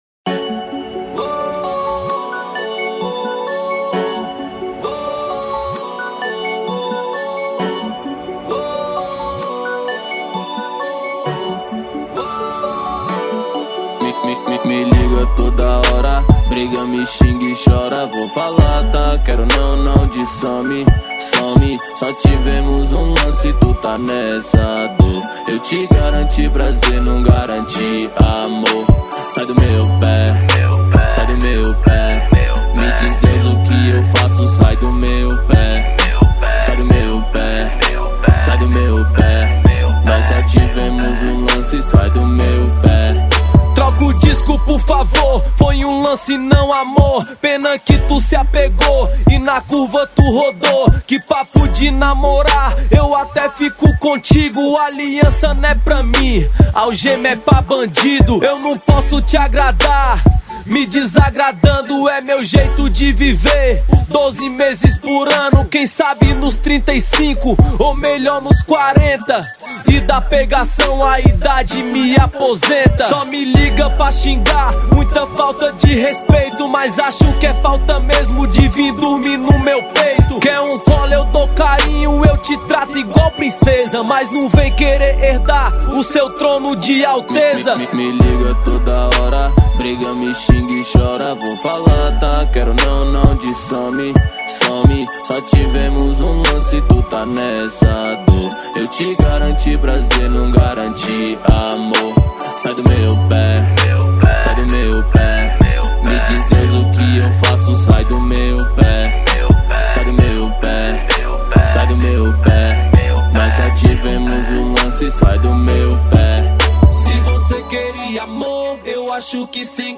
2025-03-17 19:19:55 Gênero: Rap Views